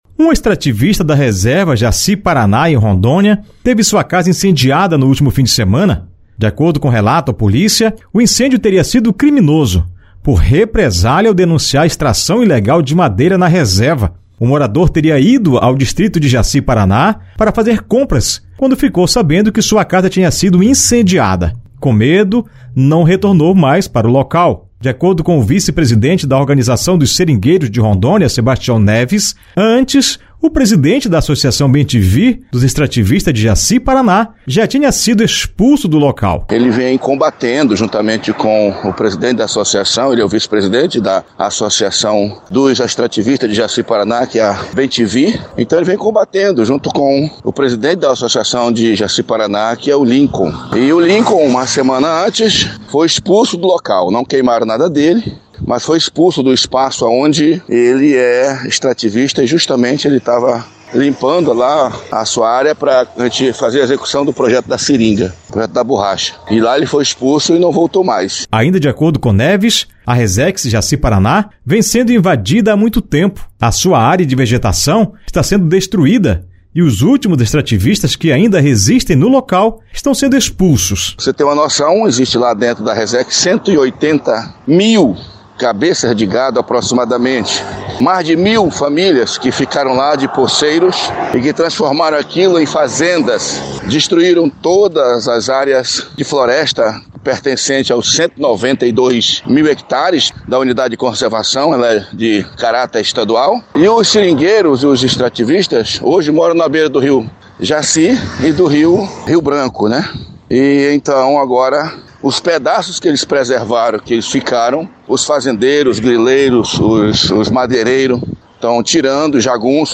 Casa de extrativista é incendiada por invasores na reserva extrativista Jaci-Paraná, em Rondônia. O extrativista denunciava vários crimes, dentre eles, a extração ilegal de madeira na reserva. A reportagem